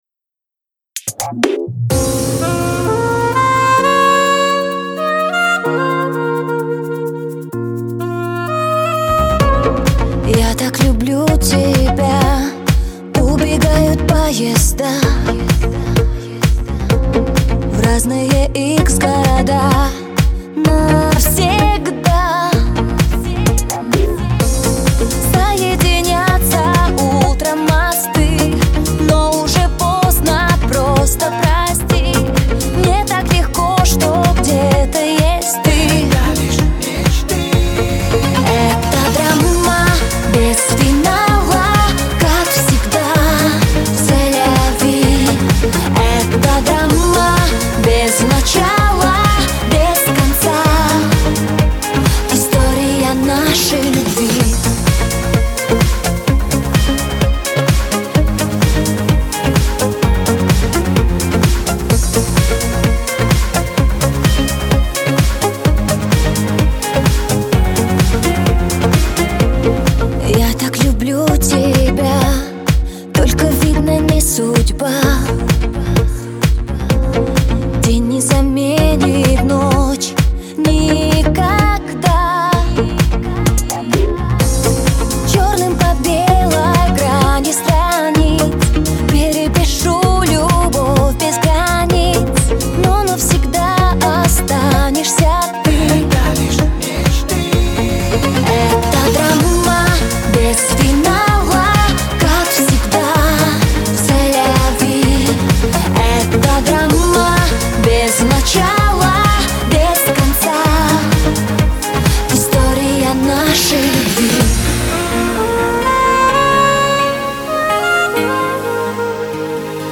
Жанр: Флон